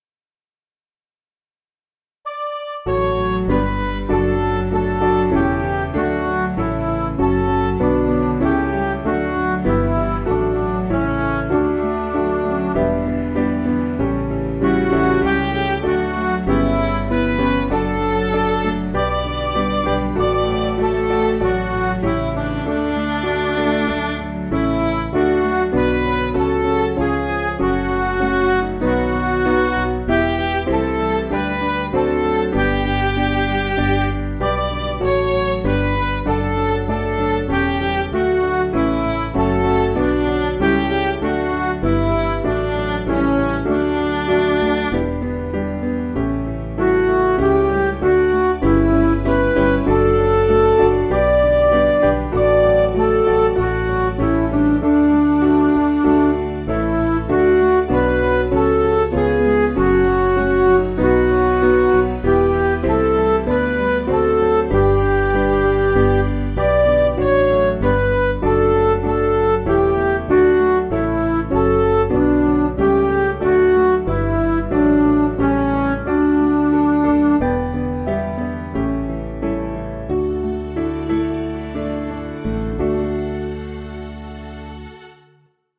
Simple Piano
Midi